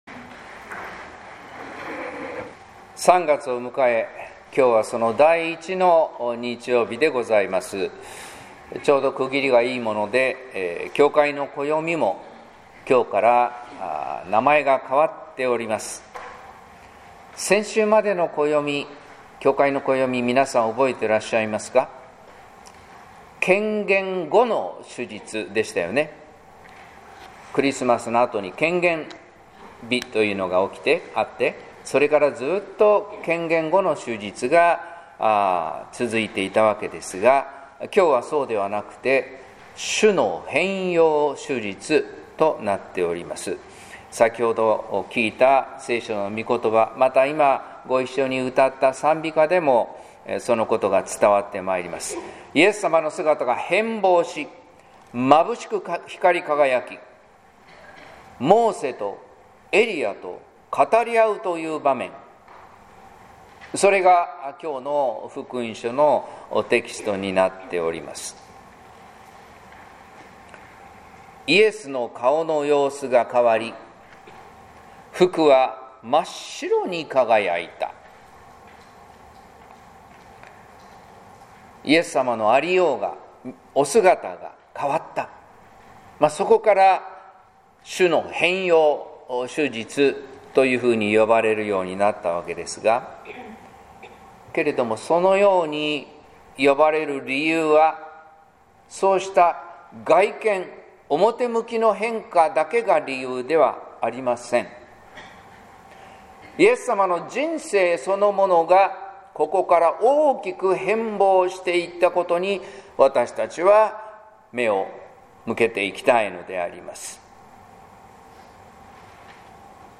説教「主とはだれか」